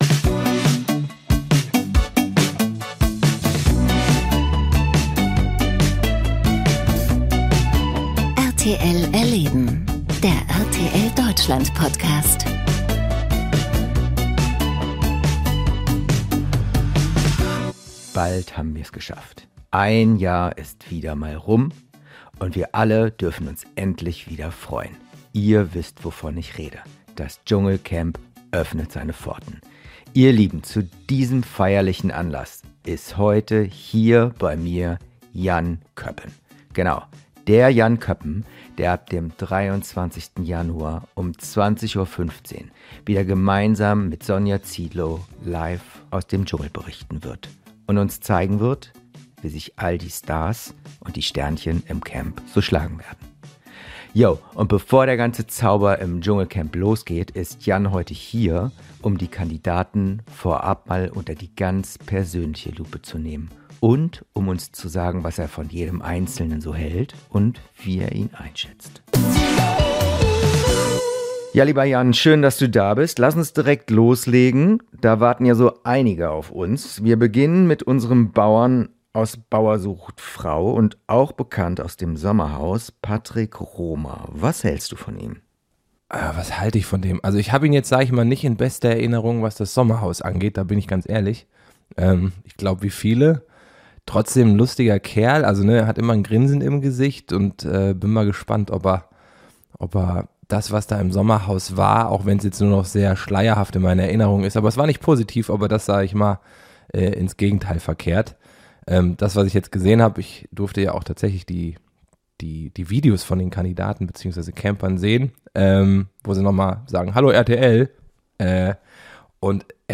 Ich bin ein Star - Holt mich hier raus!: Moderator Jan Köppen im Interview ~ RTL erleben - der RTL Deutschland Podcast